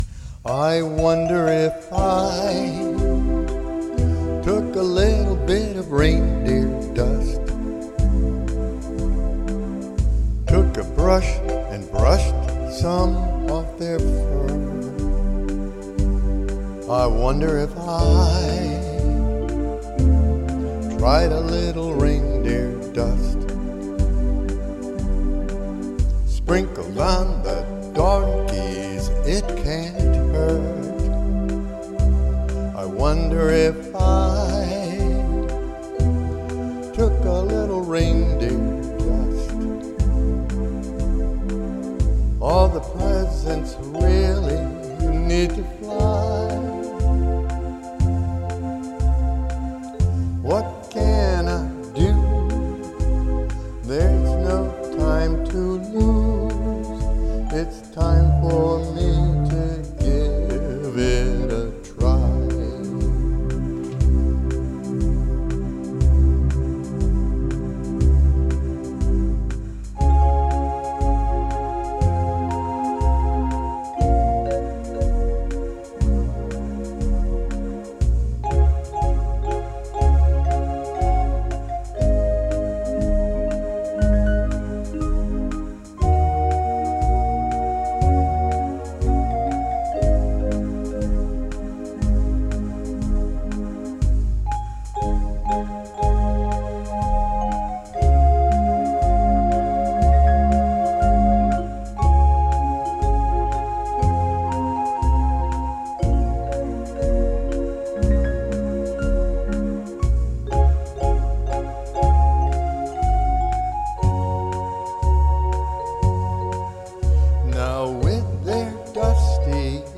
Hear the composer’s original recording: